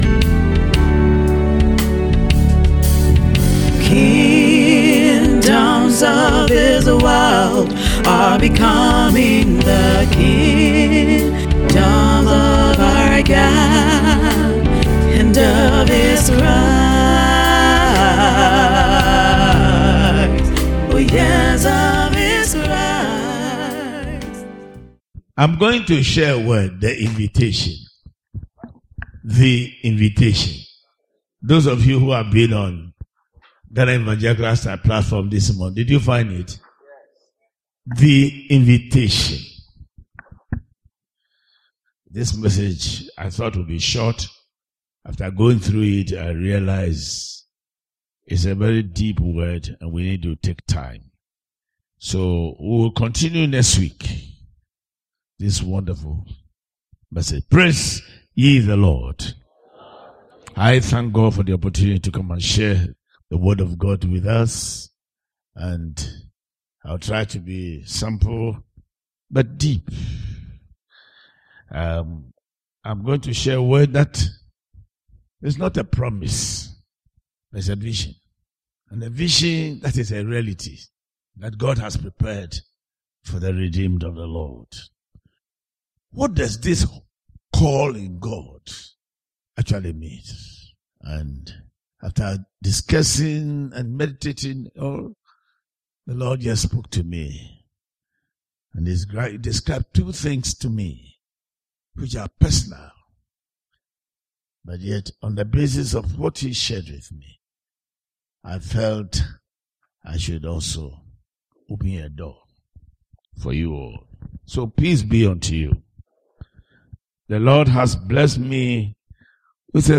Series: Audio Sermon